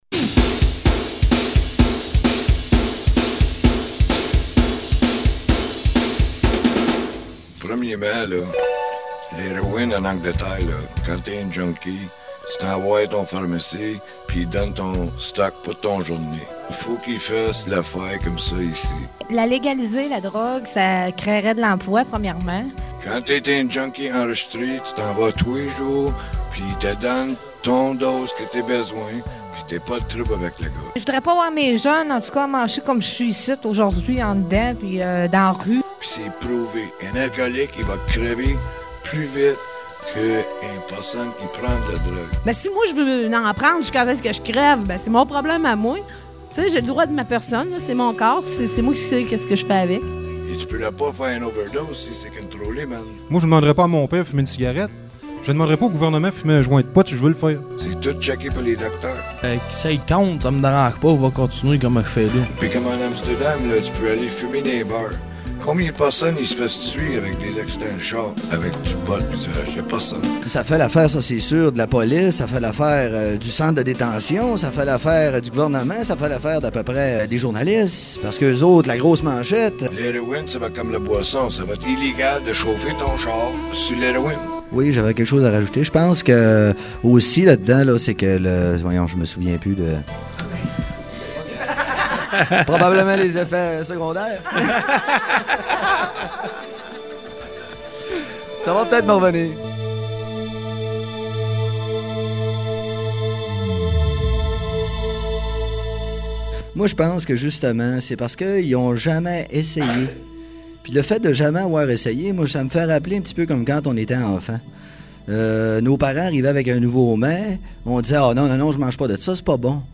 Reportage
A la prison de Bordeaux à Montréal et à la prison d�Orsainville à Québec; j'ai questionné une vingtaine de détenus, hommes et femmes dont la plus part sont incarcérées à cause de la drogue.